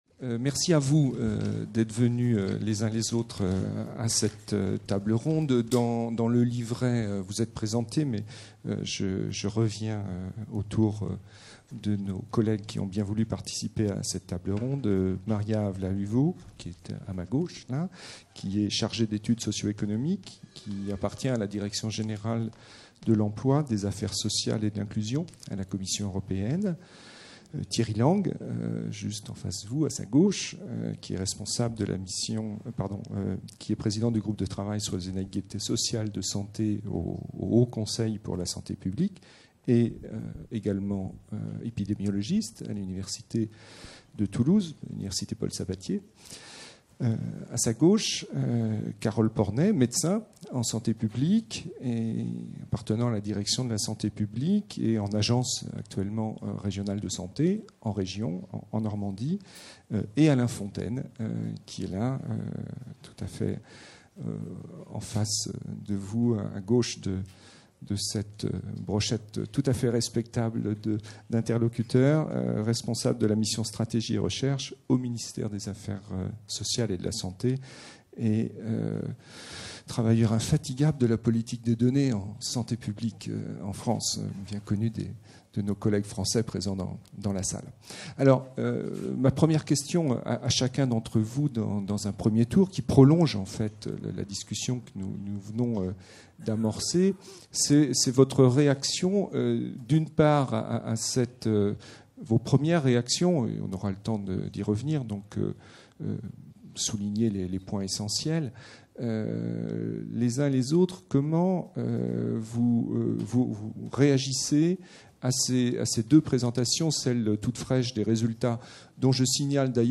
ESS Topline results presentation - Social Inequalities in Health - ROUND TABLE | Canal U